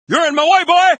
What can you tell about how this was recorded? Several attempts have been made to catalogue as many of these as can be found... but the problem has always been in the collection.